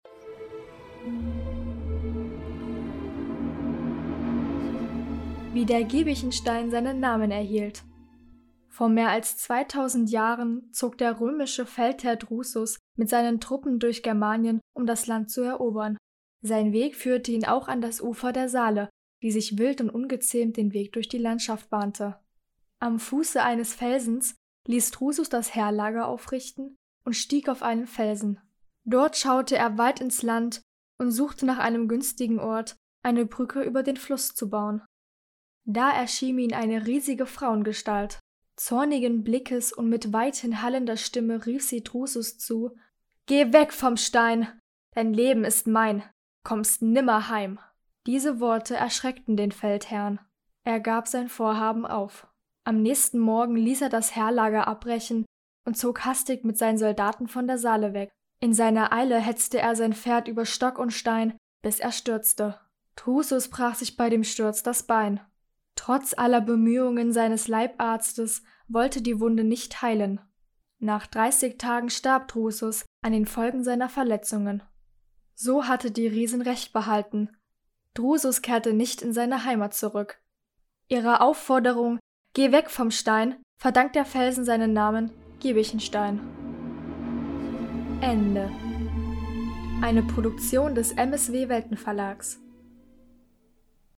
Eine Sage aus Halle (Saale) vorgelesen von der Salzmagd des